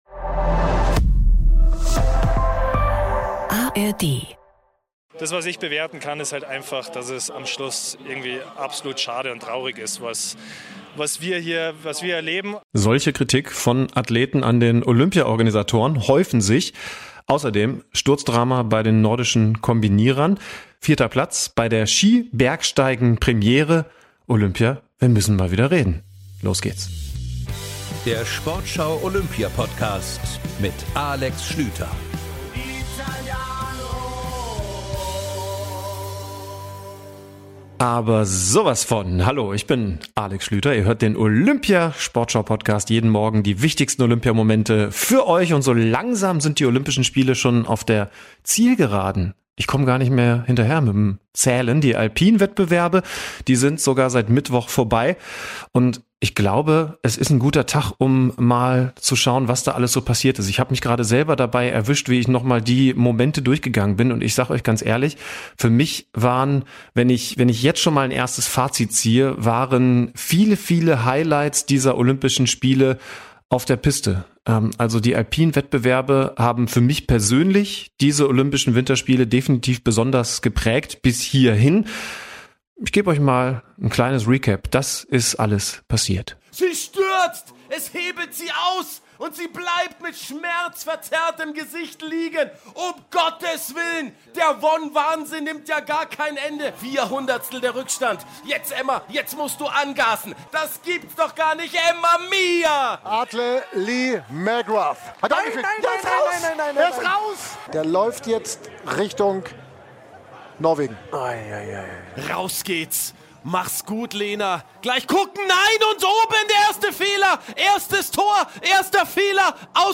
Alex Schlüter und Felix Neureuther werten die alpinen Wettbewerbe bei den Olympischen Spielen aus.